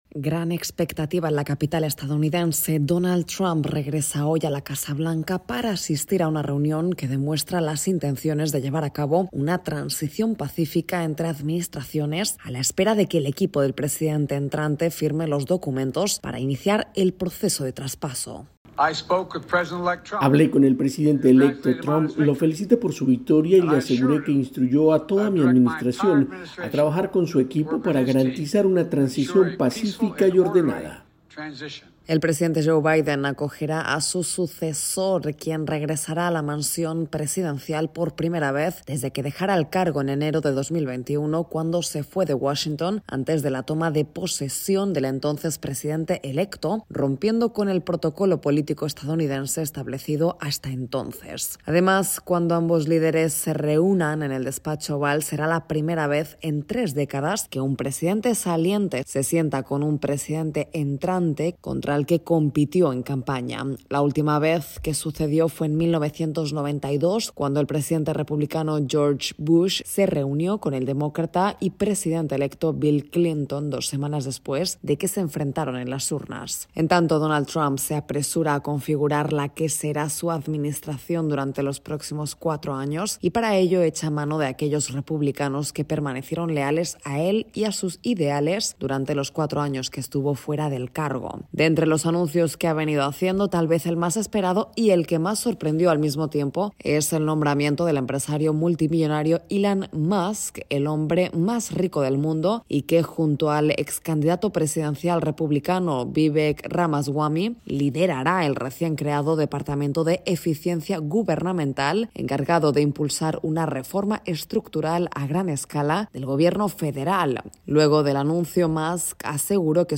Donald Trump continúa haciendo nominaciones clave a medida que da forma a su equipo de Gobierno, entre los que se incluye el magnate tecnológico Elon Musk, y hoy visita la Casa Blanca para reunirse con el presidente Joe Biden. Informa